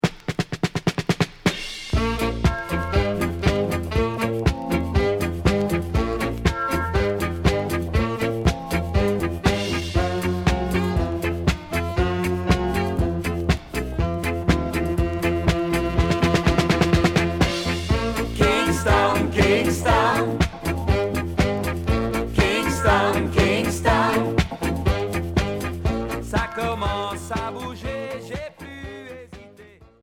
Groupe Franco-belge
Reggae